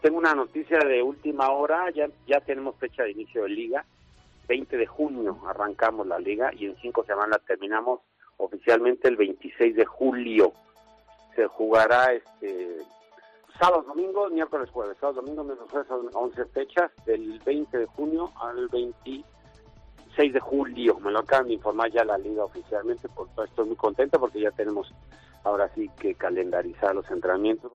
AUDIO: El entrenador del Leganés habló en MarcaClaro sobre el posible inicio de LaLiga tras el parón del coronavirus.